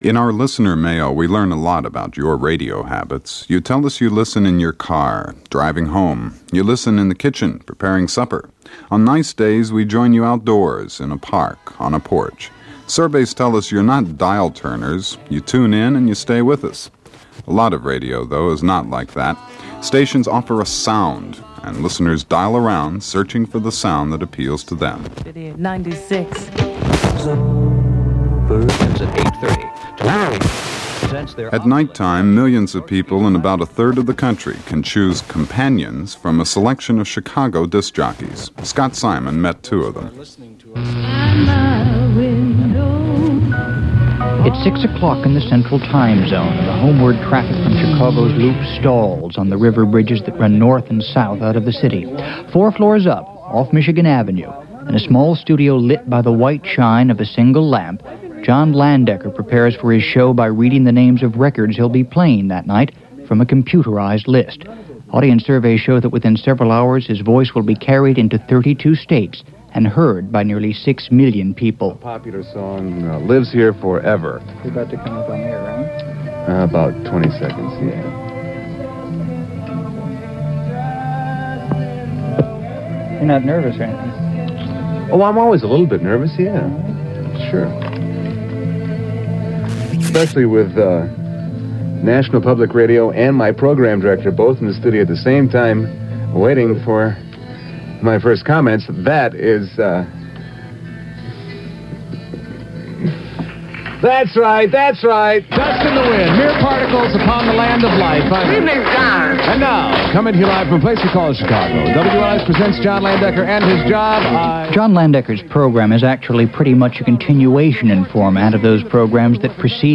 This aired nationally on NPR, and locally in Ann Arbor on the NPR station there.
The clip, by the way, features a classic Boogie Check…
NPR-Interview-FINAL.mp3